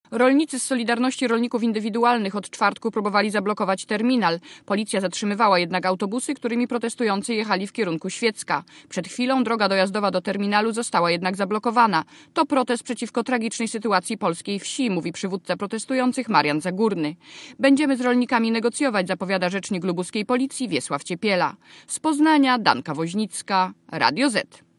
Relacja reportera Radia Zet (193Kb)